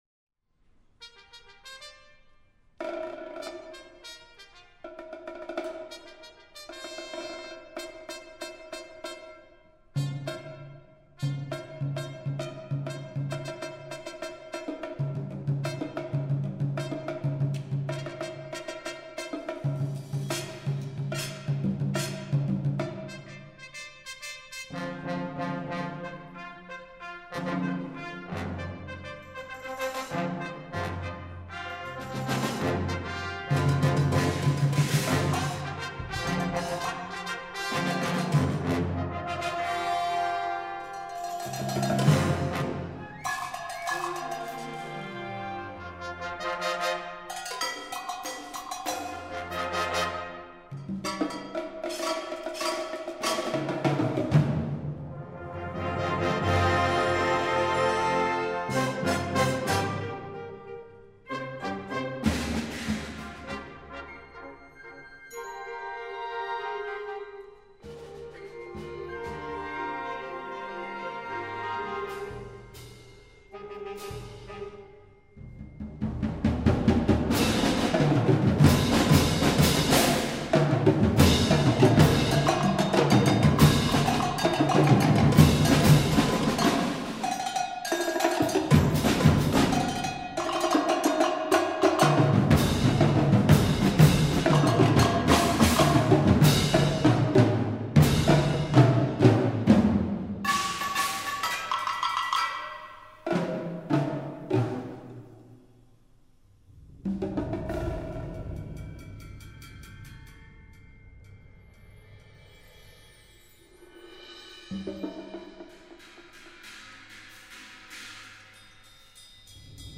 modern classical music
for large wind ensemble or concert band